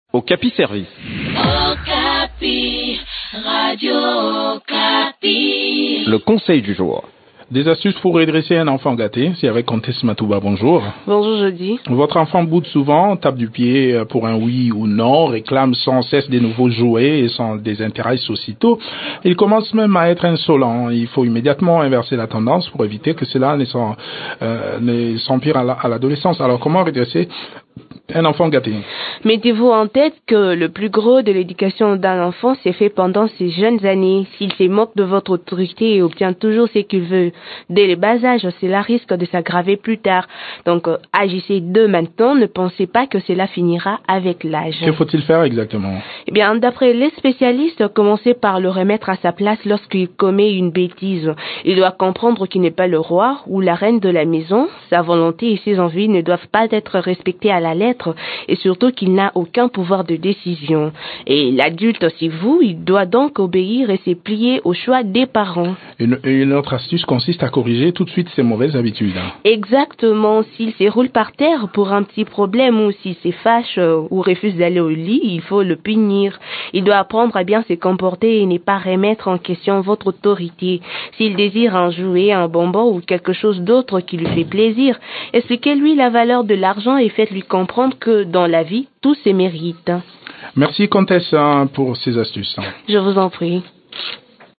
Découvrez des conseils pratiques dans cette chronique